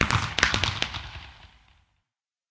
twinkle_far1.ogg